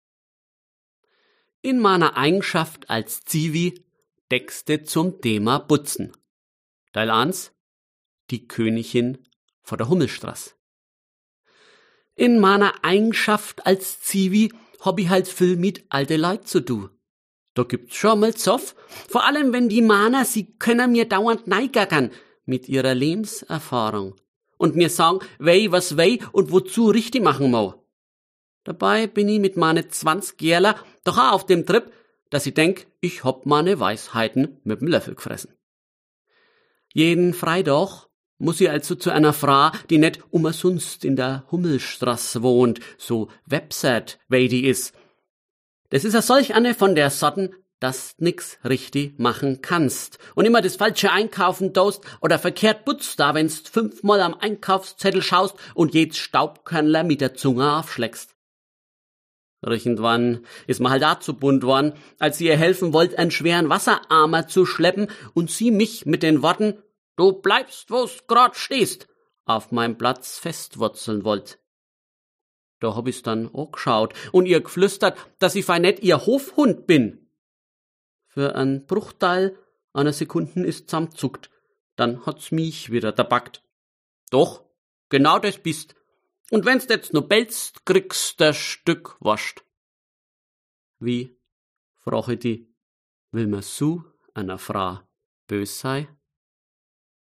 Liebe Freundinnen und Freunde des guten Wortes, liebe Fans des Fränkischen,